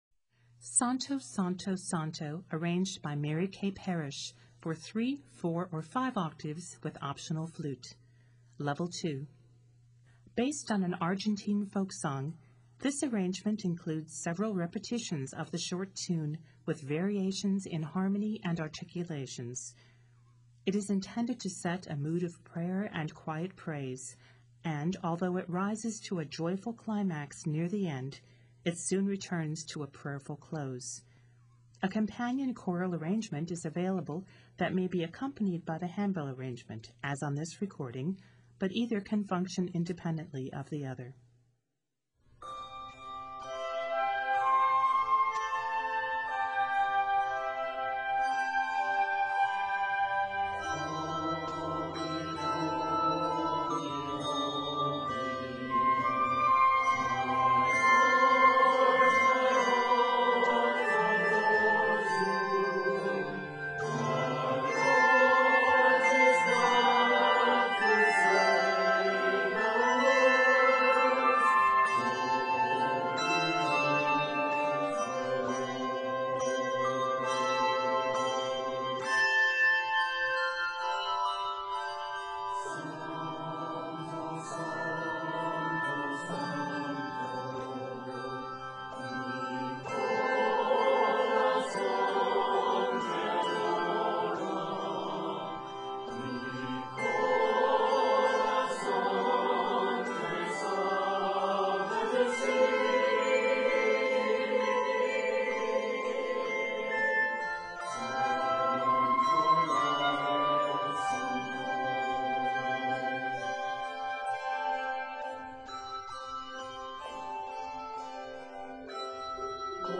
Argentine Folk Tune Arranger